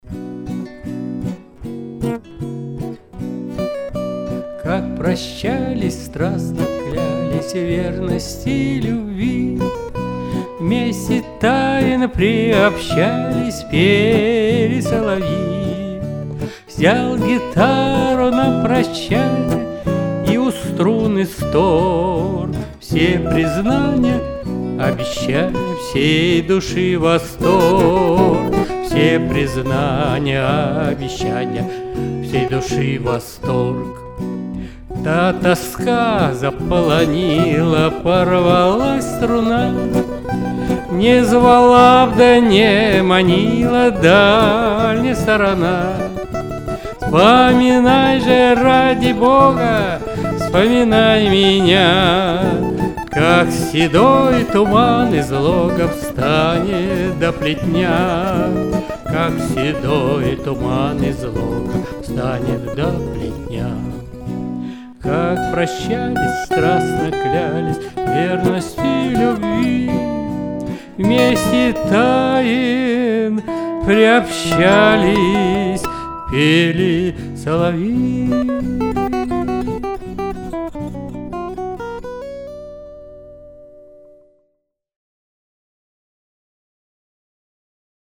вокал, гитара
Записано в студии